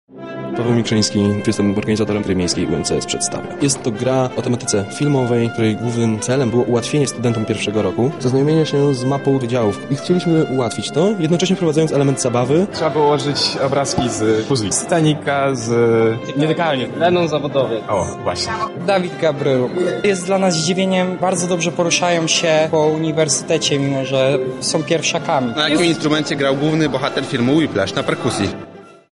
Graczom towarzyszył nasz reporter.
Relacja